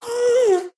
moan3.wav